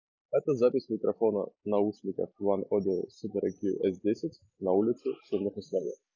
Микрофон:
Внутри каждого наушника по 2 микрофона + с помощью ИИ алгоритма идет обработка сигнала для улучшения итогового качества.
В шумных условиях:
oneodio-supereq-s10-ulica-shumno.m4a